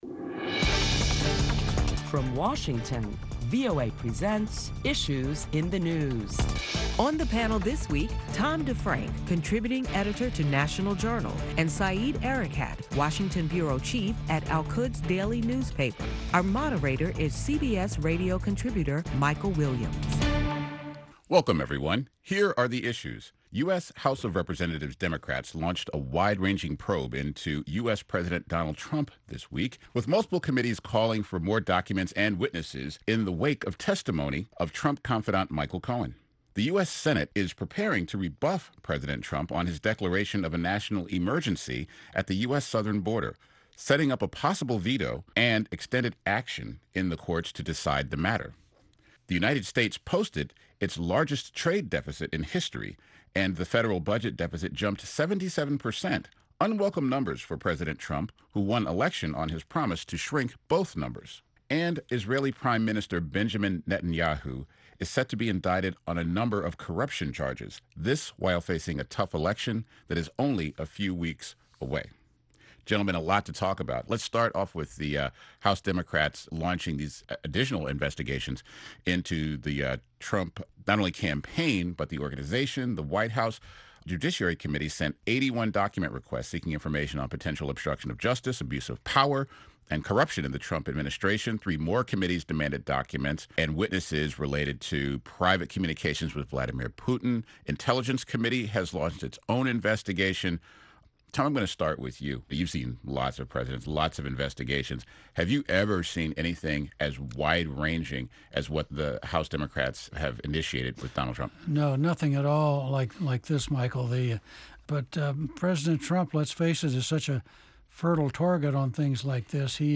Listen to a discussion among prominent Washington journalists as they deliberate the week's top stories including the launching of a new probe of President Trump’s White House, campaign, and family businesses.